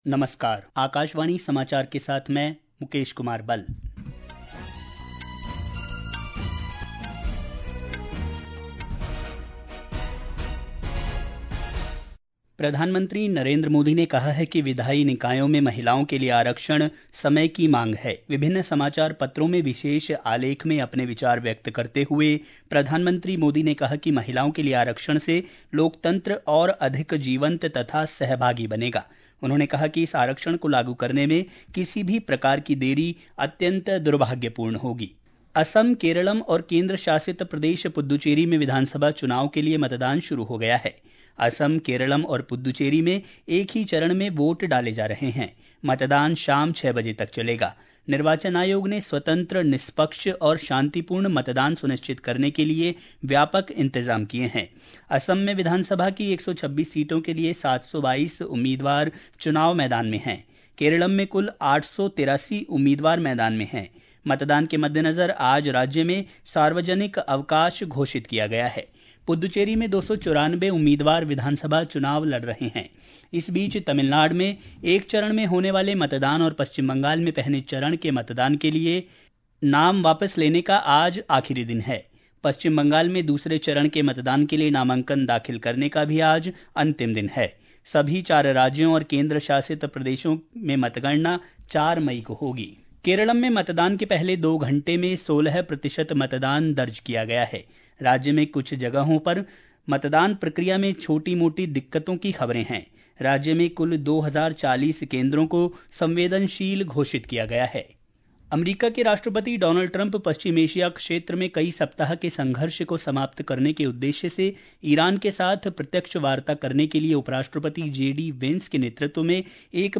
प्रति घंटा समाचार
प्रति घंटा समाचार | Hindi